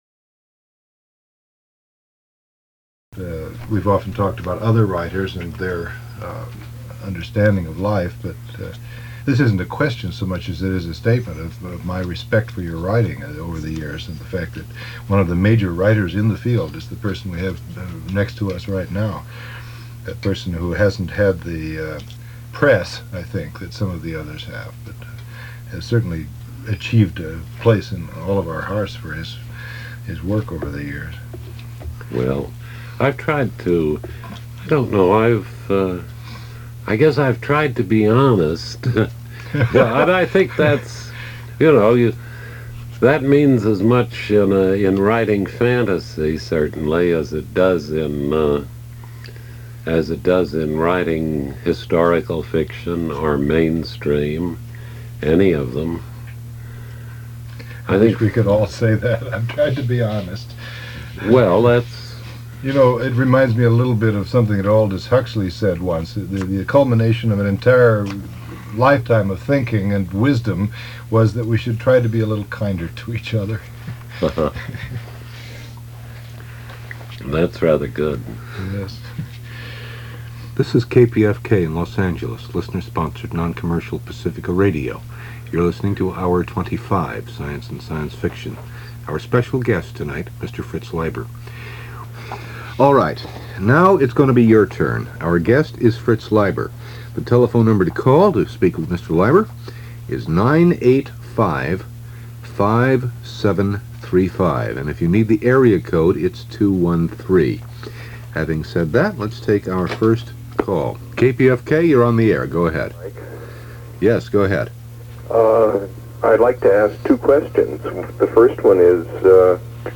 I was lucky enough to catch the interview and reading, and to tape it also; and the audio tapes have survived over 33 years in storage to become the digital audio files you are about to enjoy.